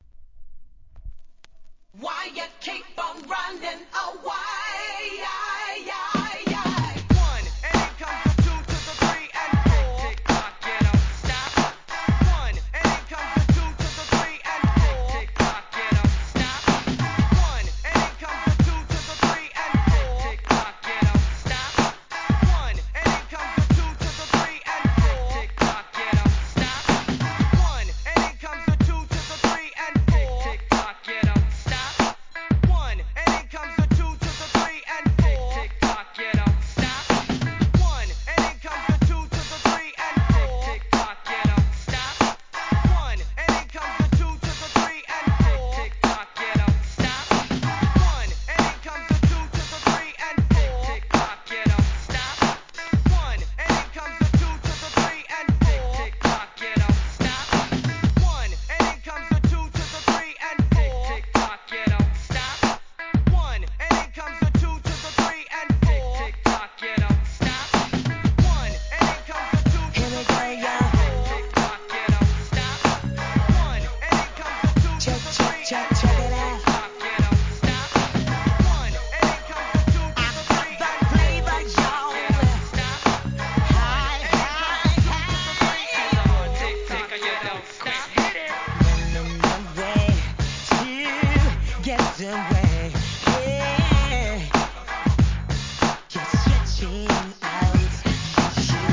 HIP HOP/R&B
タメの効いたNEW JACKなビートに後半、ラガなボーカルも混ざります!!!